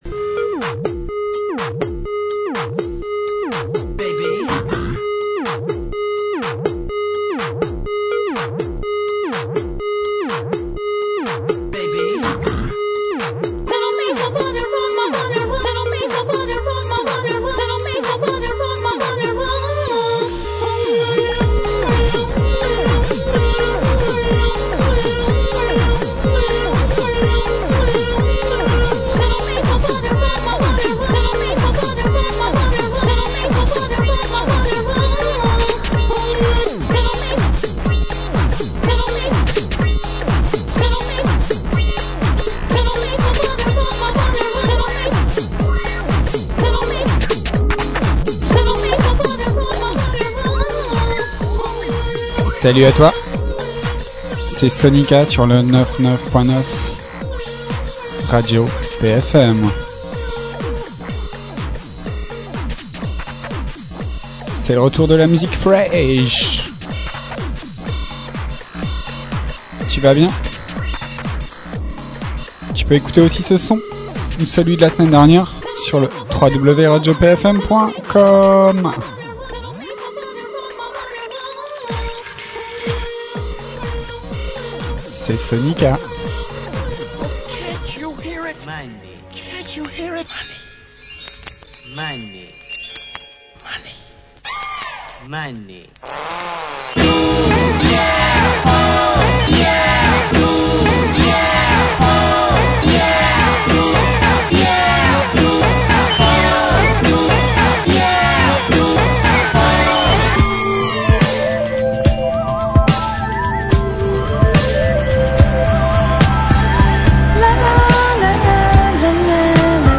SoniKa en live sur RADIO PFM 99.9 le dimanche à 17h !!